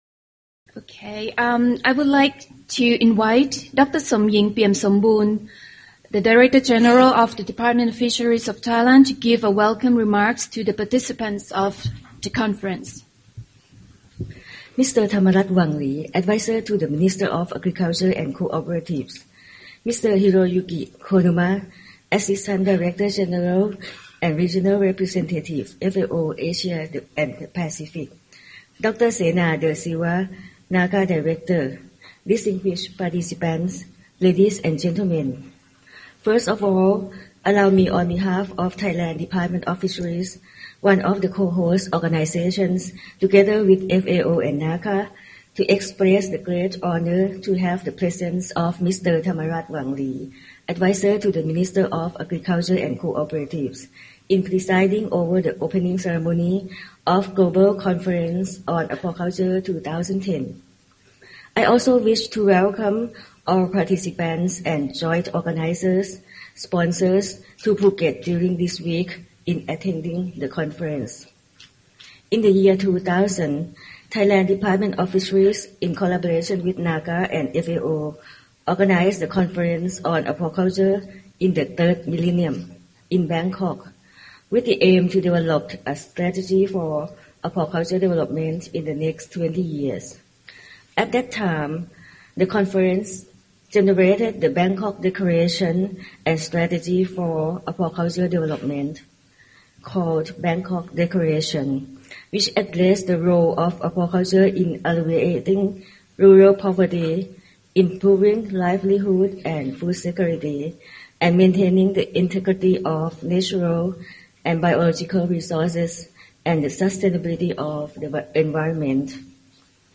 Welcome remarks made at the opening ceremony of the Global Conference on Aquaculture 2010 by Dr Somying Piumsombun, Director General of the Department of Fisheries, Thailand.